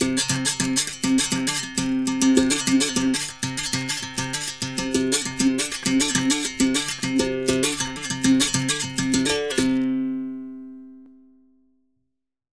Berimbao_Samba_100_1.wav